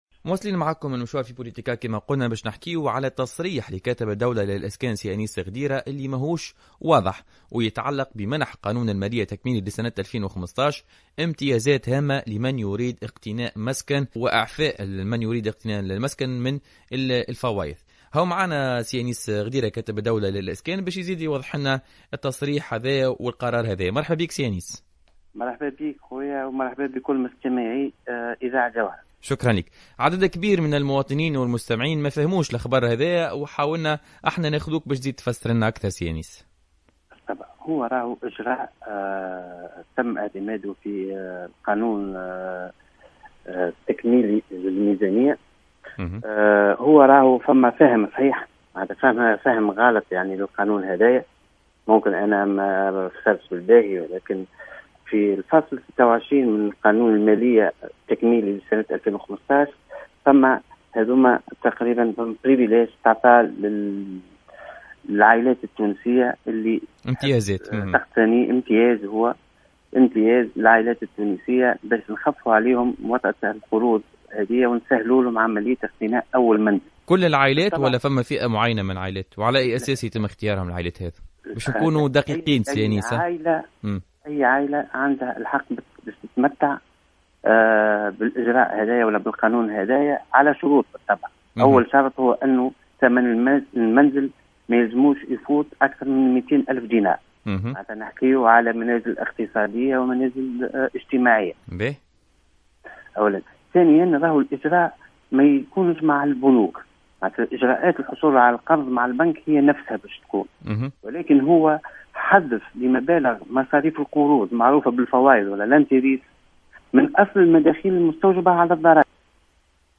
أكد كاتب الدولة للإسكان أنيس غديرة في تصريح لجوهرة أف أم في برنامج بوليتكا اليوم الجمعة 04 سبتمبر 2015 أن قانون المالية التكميلي لسنة 2015 تضمن امتيازات هامة للمواطنين الذين يريدون اقتناء مسكن تتمثل خاصة في الإعفاء من الفوائض الموظفة على القرض التي سيتم خصمها من المورد وفق قوله.